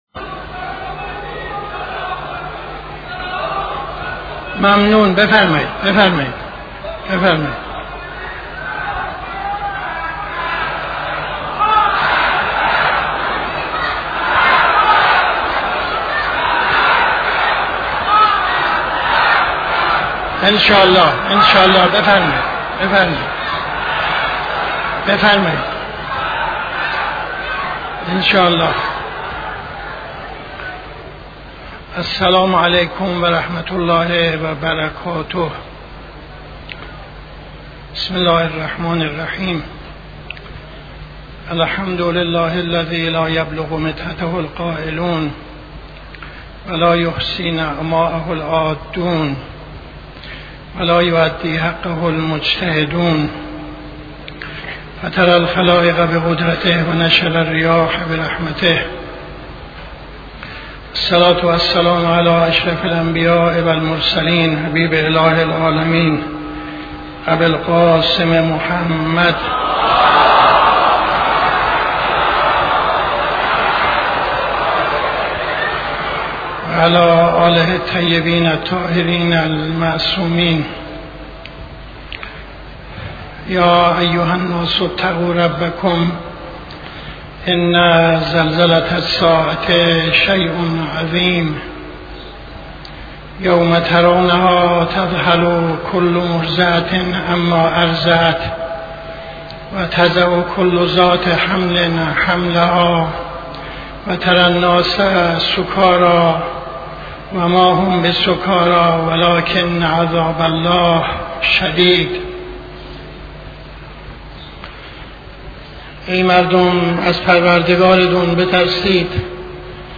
خطبه اول نماز جمعه 02-04-74